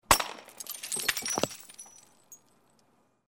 BOTELLA ROTA
Ambient sound effects
Descargar EFECTO DE SONIDO DE AMBIENTE BOTELLA ROTA - Tono móvil
botella_rota.mp3